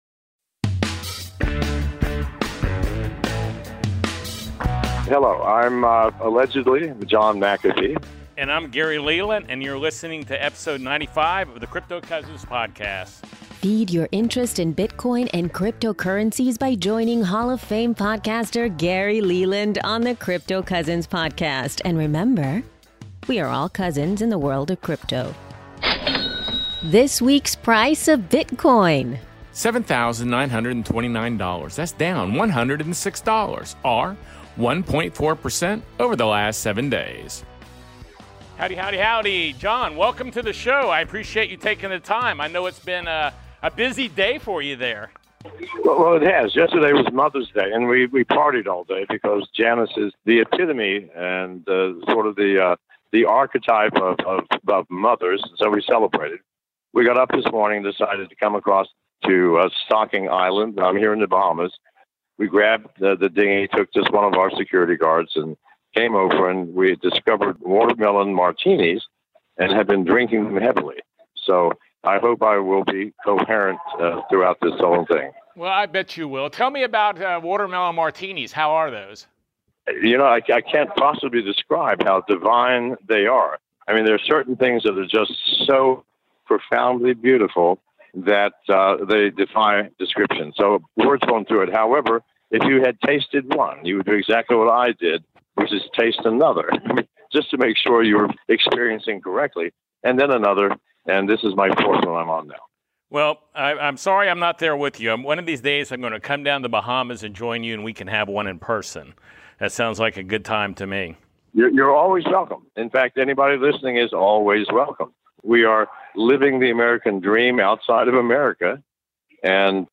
Bitcoin and Mining Expert John McAfee Interview and Q&A